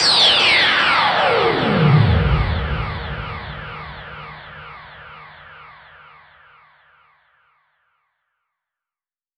VES2 FX Downlifter
VES2 FX Downlifter 28.wav